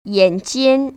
[yănjiān] 얜지엔  ▶